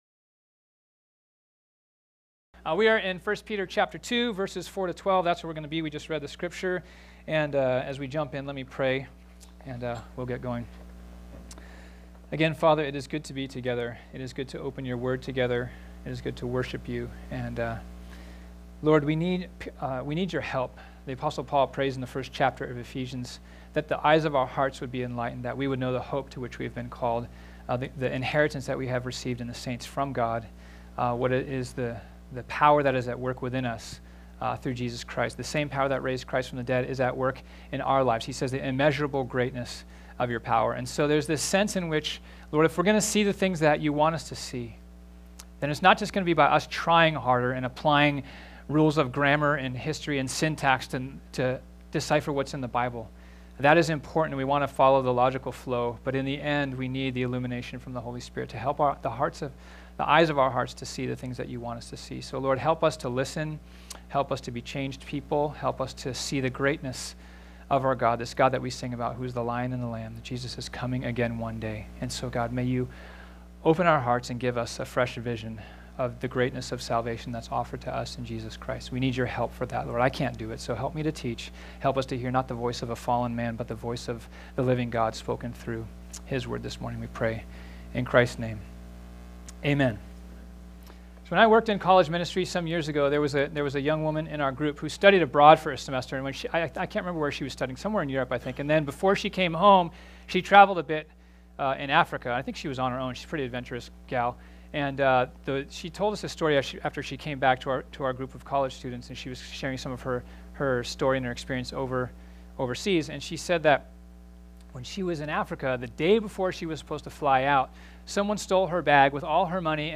This sermon was originally preached on Sunday, March 4, 2018.